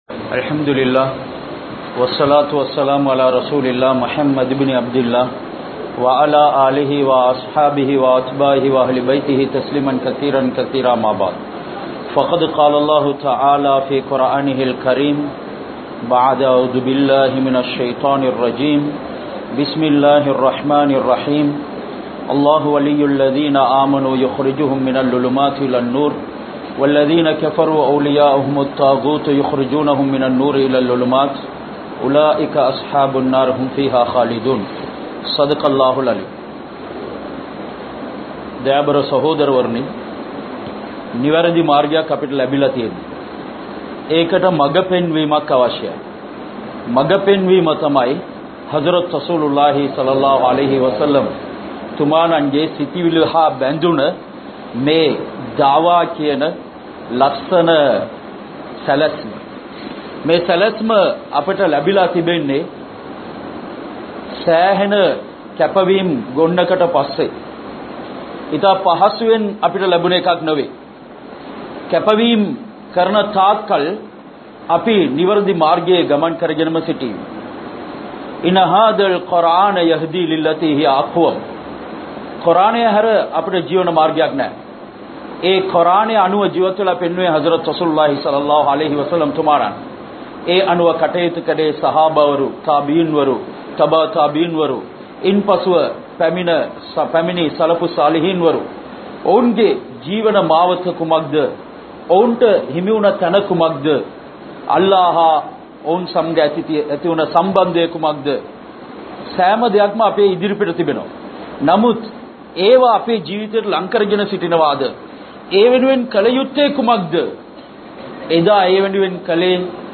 Thiyagathudan Seyyum Ulaippu(தியாகத்துடன் செய்யும் உழைப்பு) | Audio Bayans | All Ceylon Muslim Youth Community | Addalaichenai
Wathtala, Gongitota Masjith